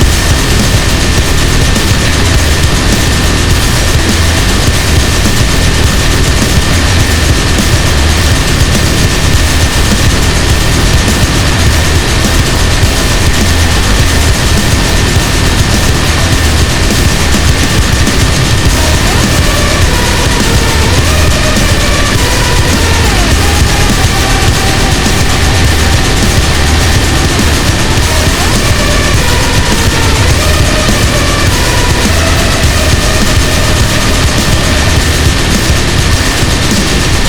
gatling_shoot.wav